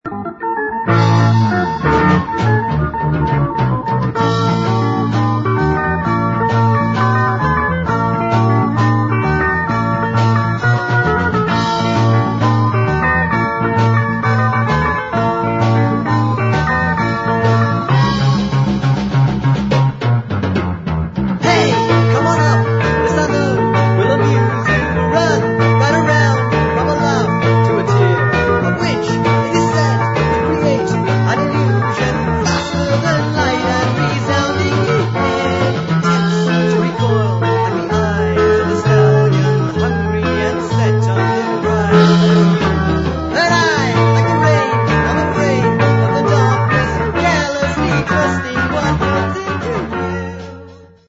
We taped the results as we went along.
Clips (mp3 medium quality stereo)